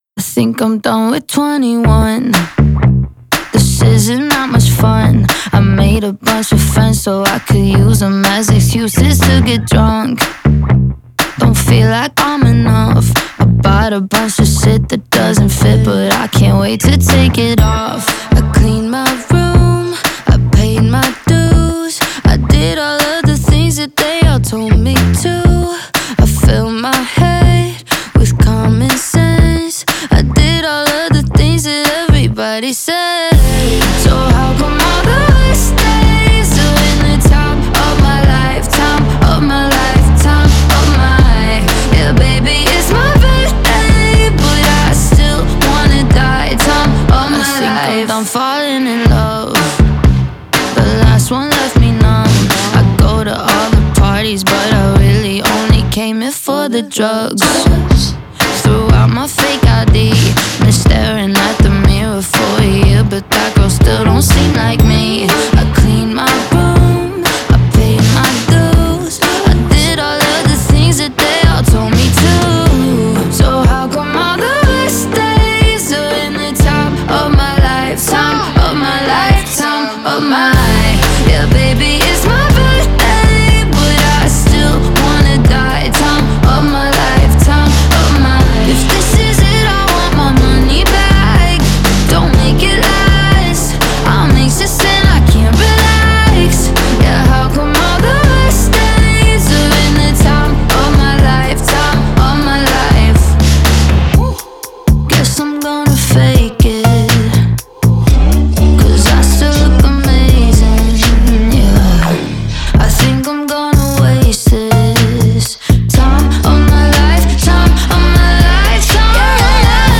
энергичная поп-рок песня